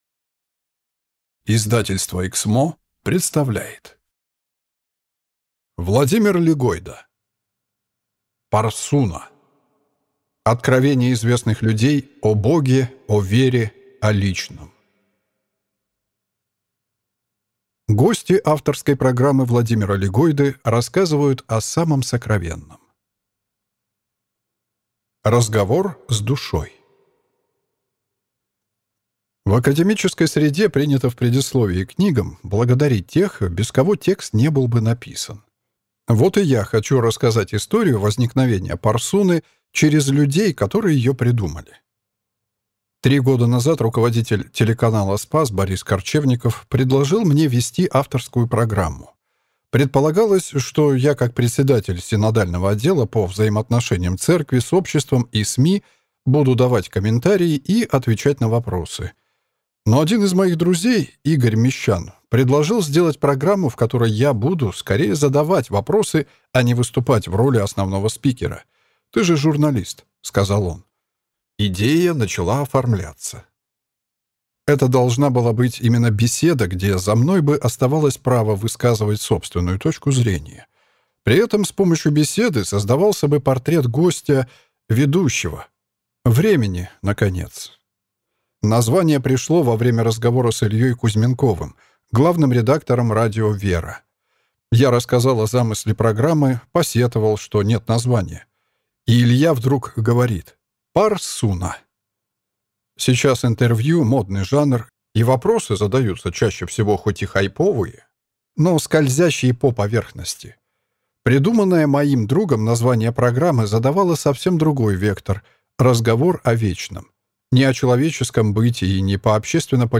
Аудиокнига Парсуна. Откровения известных людей о Боге, о вере, о личном | Библиотека аудиокниг